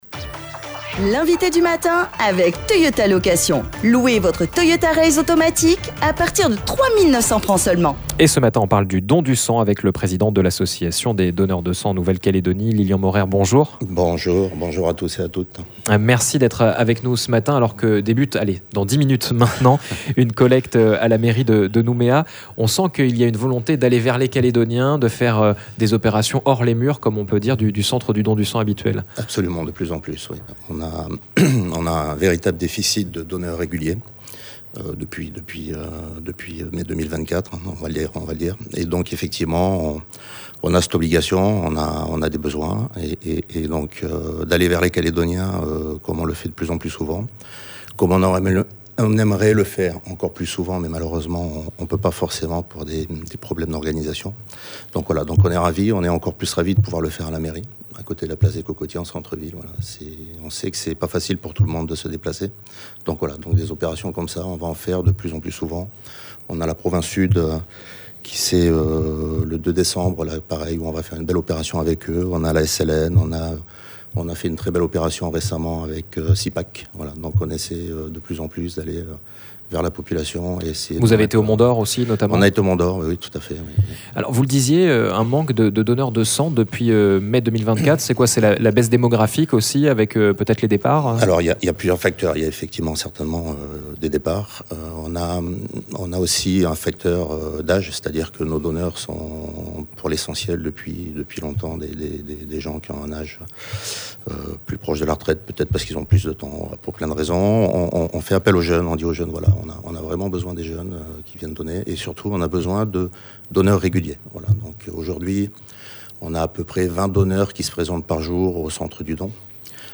L'INVITÉ DU MATIN